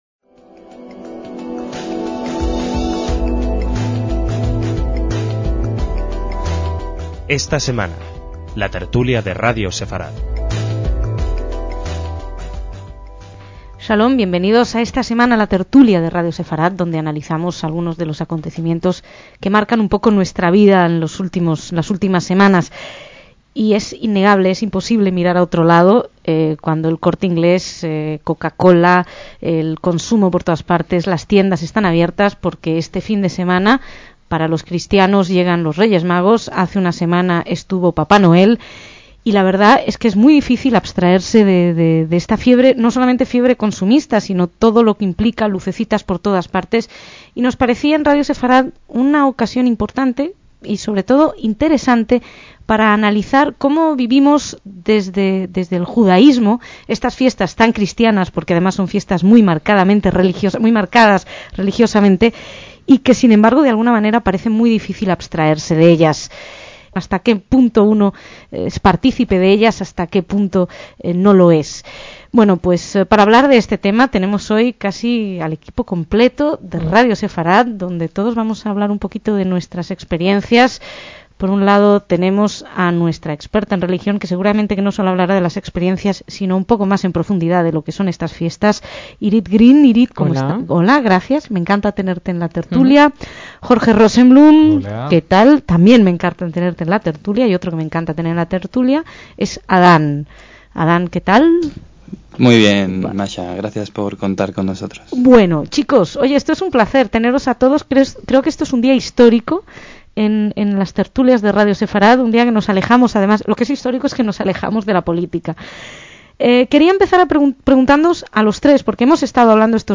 DECÍAMOS AYER (5/1/2008) - ¿Cómo viven los judíos las fiestas religiosas cristianas? Una pregunta sobre los que han debatido varios miembros de Radio Sefarad entonces.